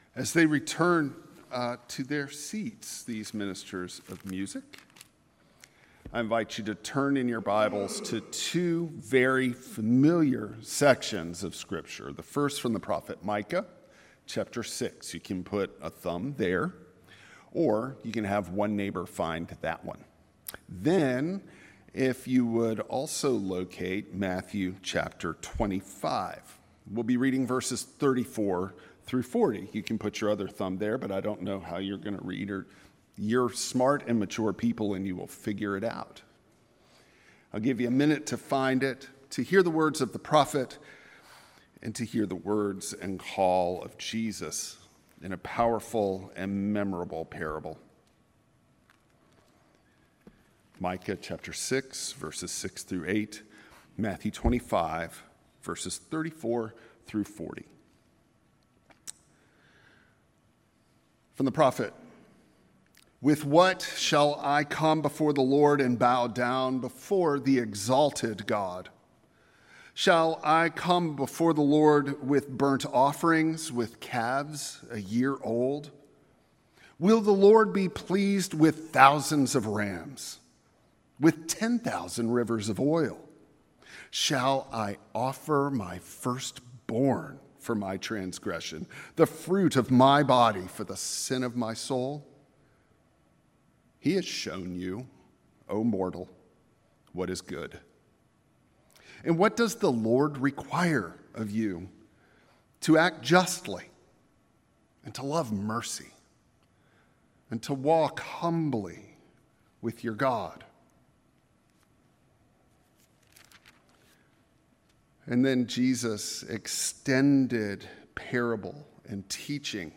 Preacher
Service Type: Traditional Service